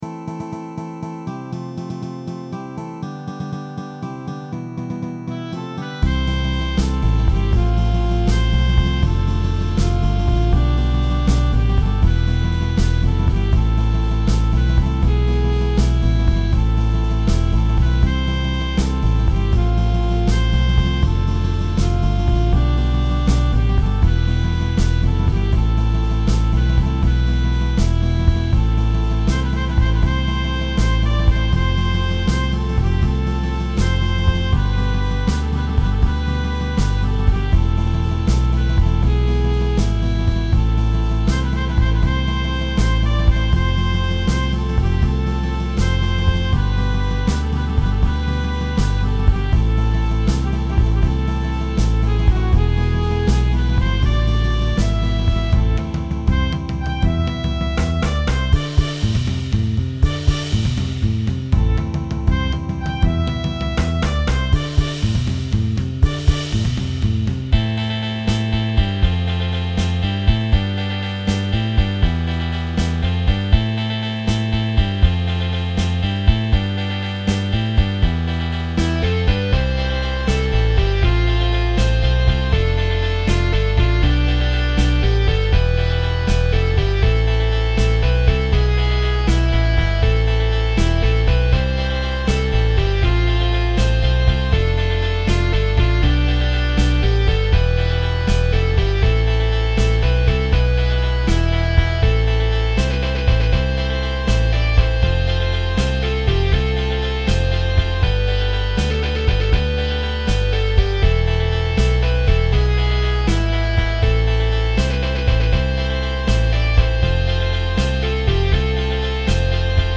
기악곡입니다.
스파게티 ��웨스턴 영화 음악처럼 들립니다.